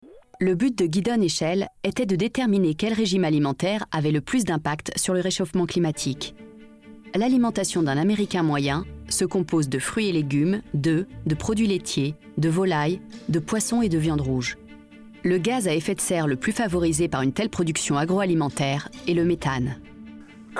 Narration : Planeat : manger sain pour la planète